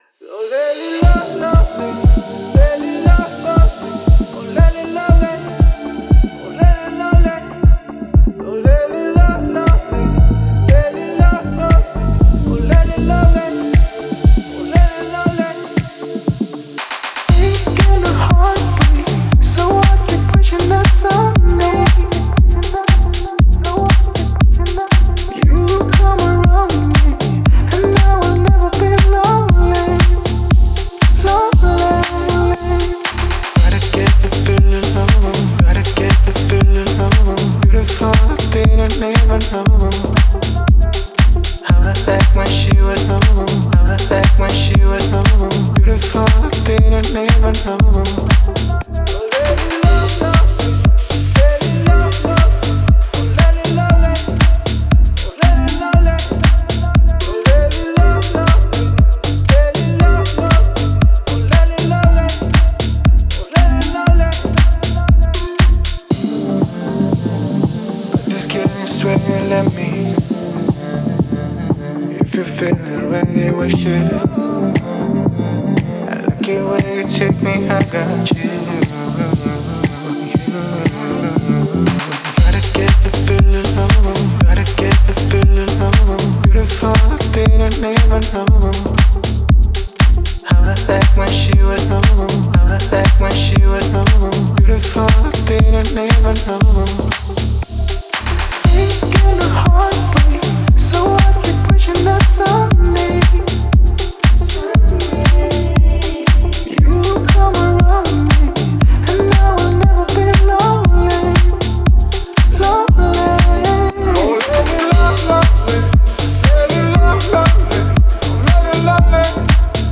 а динамичные биты заставляют двигаться в такт.